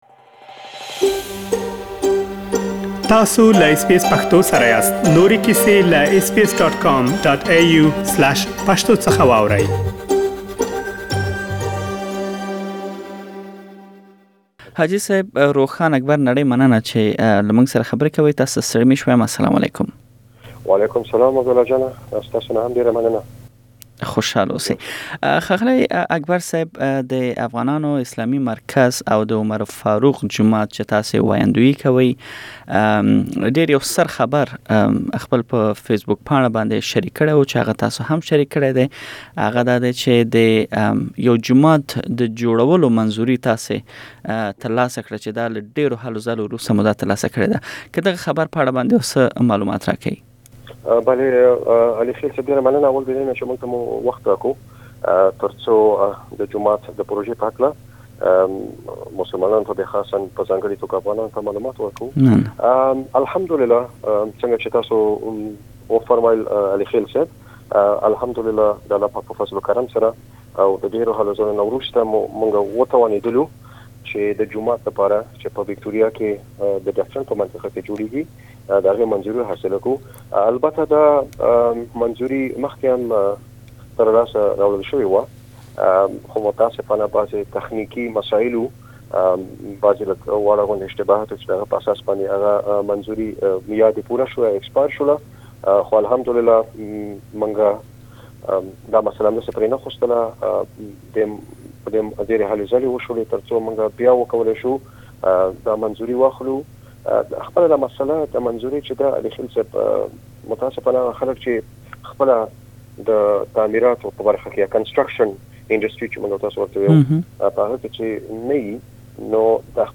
and you can listen to the full interview in Pashto language.